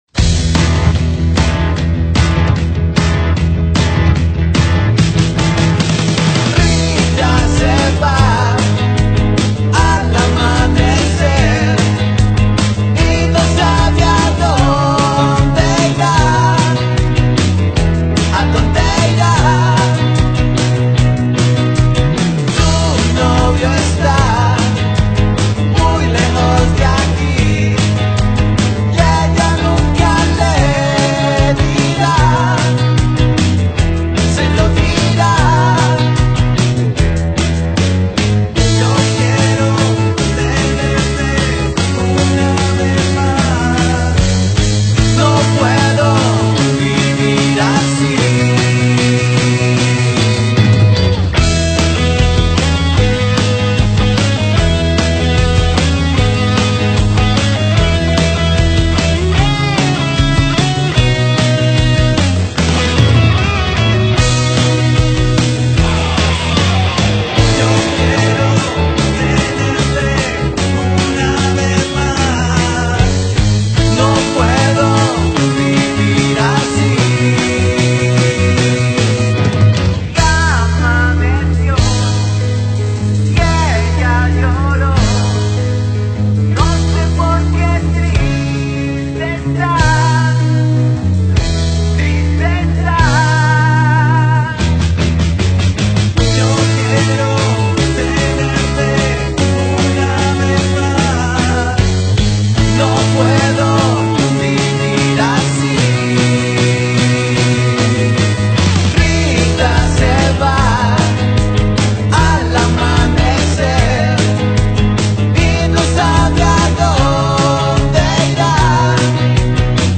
Músico, cantautor y multi-instrumentista
Rock latino